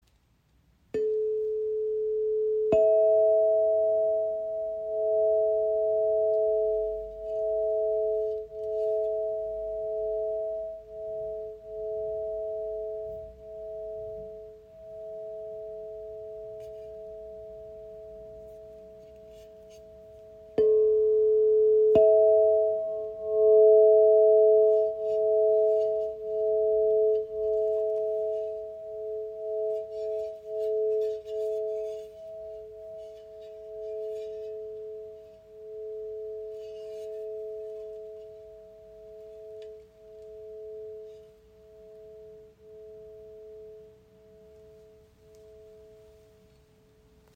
Die Wave A/E in 432 Hz ist ein handgefertigtes Klanginstrument in der heiligen Quinte das Harmonie, Ruhe und Energiefluss schenkt.
Der Klang breitet sich weich und klar im Raum aus, ohne zu dominieren, und lädt Dich ein, langsamer zu werden, tiefer zu atmen und wieder bei Dir selbst anzukommen.